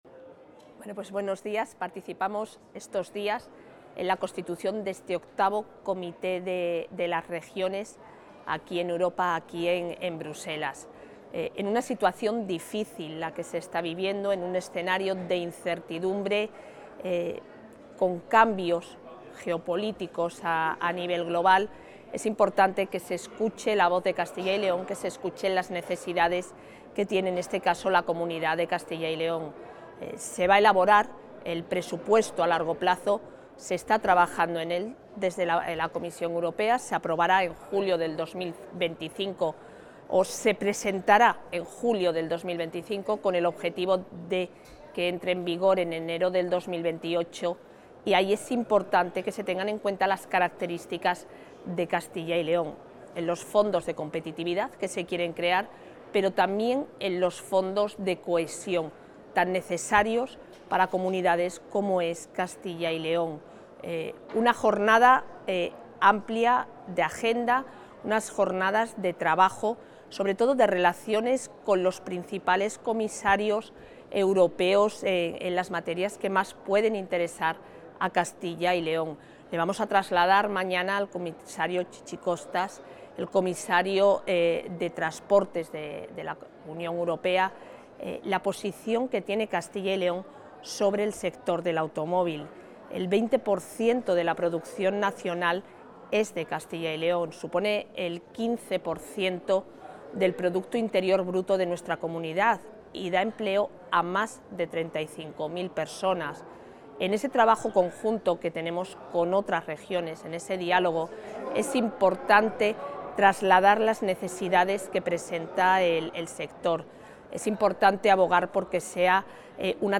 Intervención de la vicepresidenta.